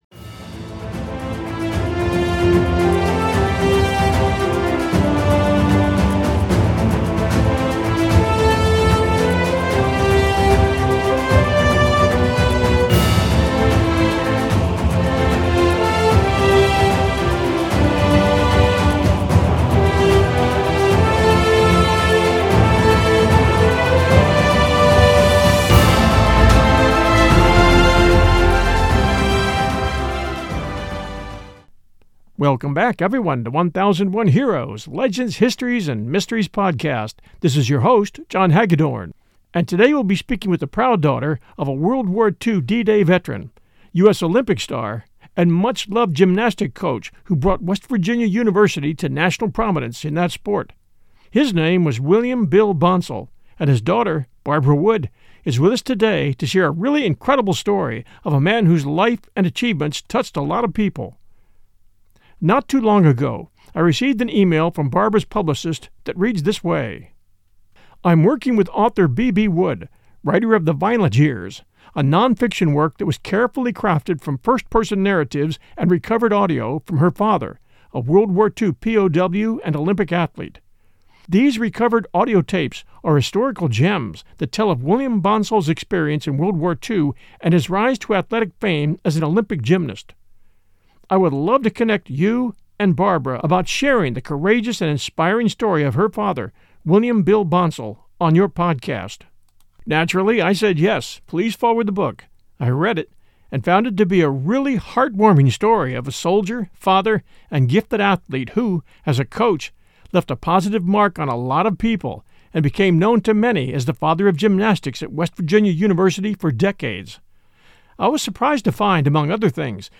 Forty years later, he recorded his life experiences in a series of audio tapes that cover the decade between 1940 and 1950, a period he called “violent” because of the tumultuous upheavals, both positive and negative, of the times.